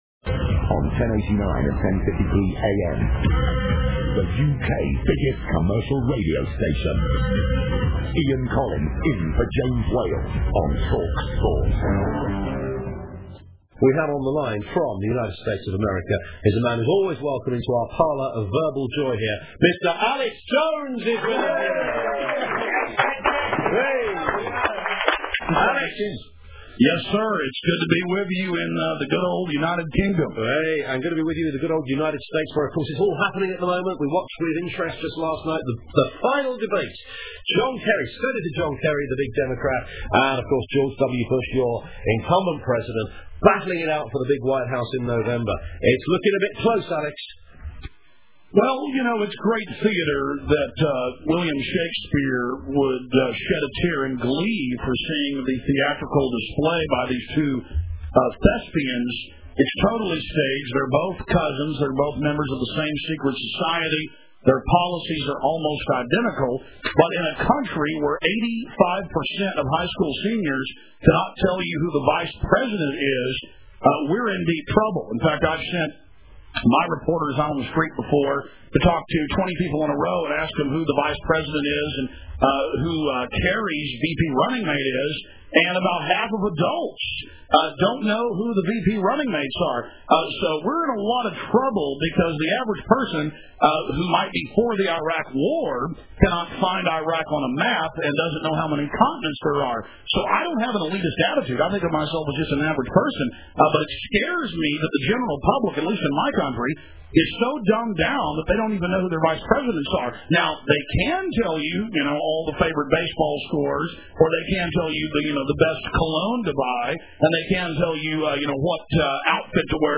Alex Jones guests on TalkSport, the UK's number one commerical radio station, talking about 9/11 and the New World Order on Thursday Oct. 14, 2004